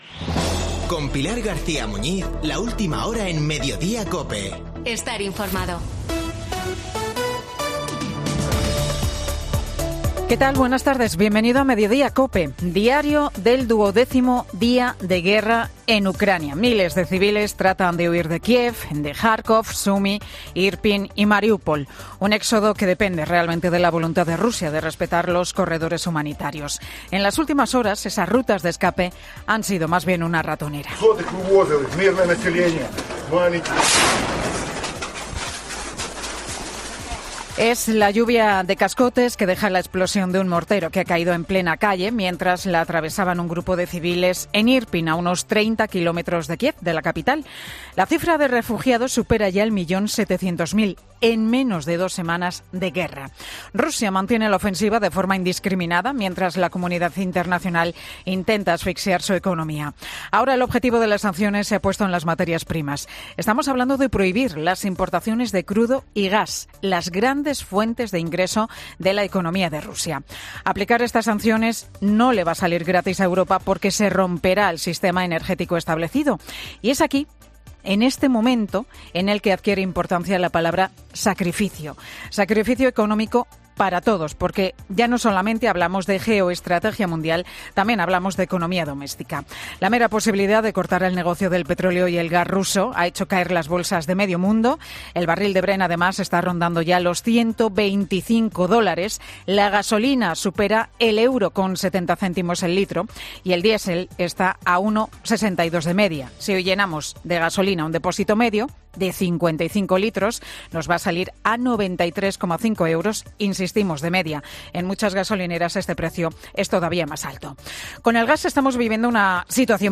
El monólogo de Pilar García Muñiz, en Mediodía COPE